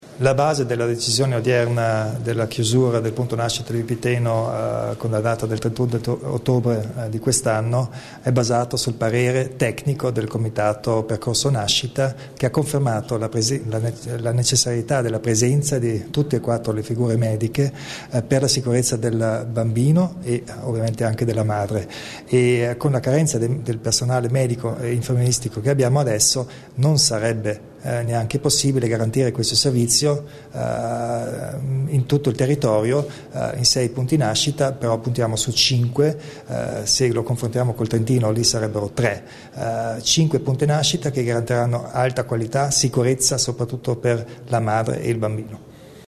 Il Presidente Kompatscher spiega la decisione sul futuro del punto nascita di Vipiteno